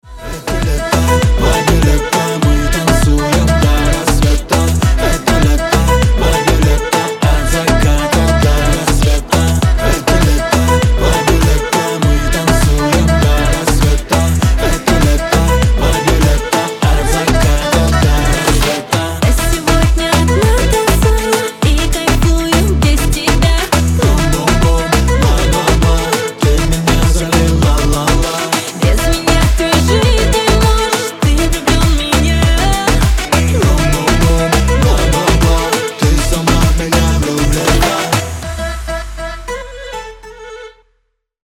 Танцевальный трек, который напомнит Вам о прошедшем лете